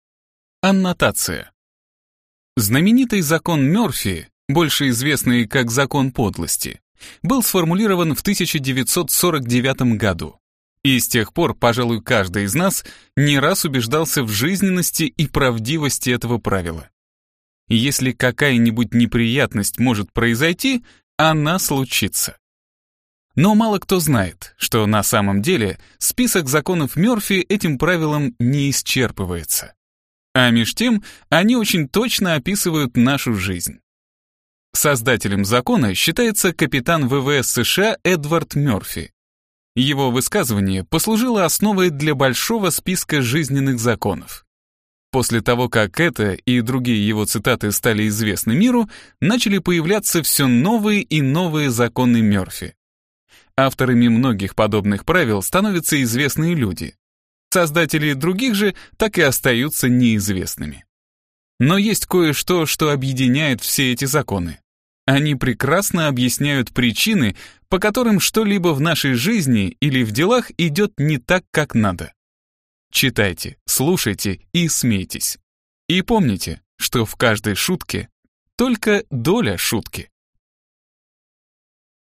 Аудиокнига Законы Мерфи | Библиотека аудиокниг
Прослушать и бесплатно скачать фрагмент аудиокниги